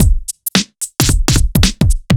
Index of /musicradar/off-the-grid-samples/110bpm
OTG_Kit 2_HeavySwing_110-A.wav